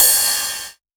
Tr8 Cymbal 02.wav